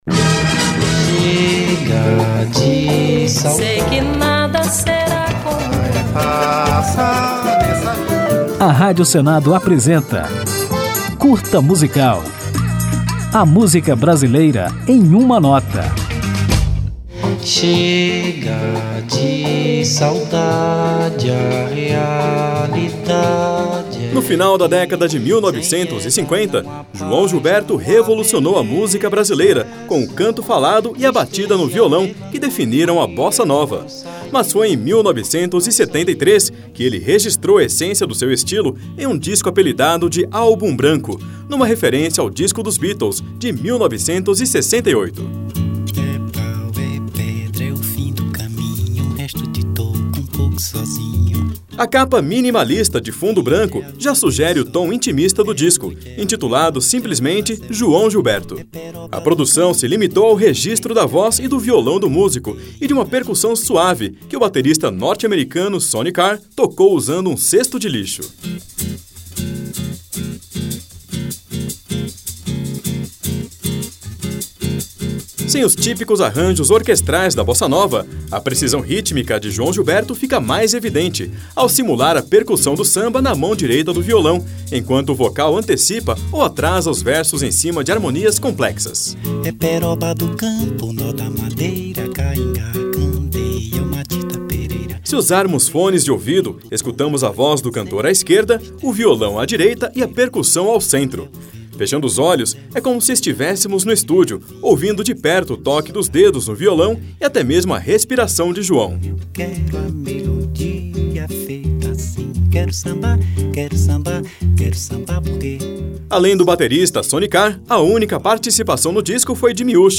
Depois de apresentada a história e as curiosidades sobre este lendário disco de João Gilberto, ouviremos uma de suas faixas, o samba Falsa Baiana.